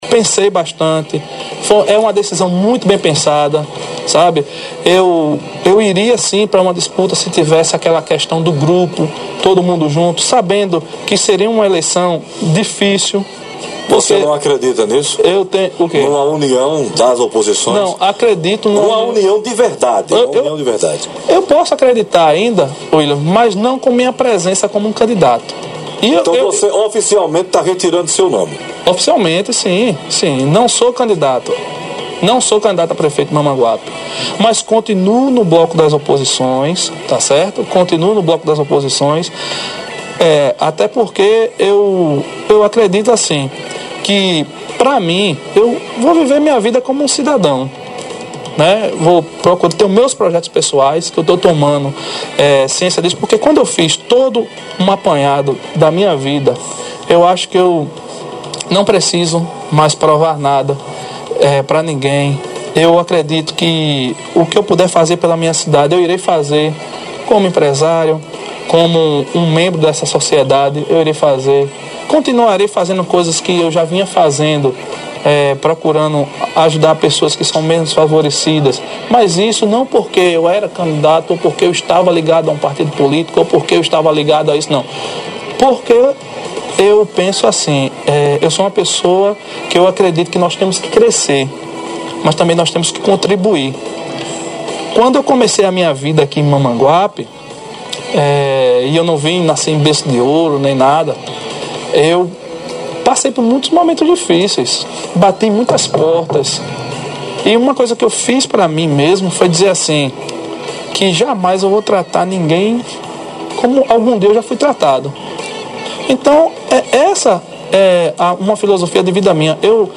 Trecho da entrevista abaixo: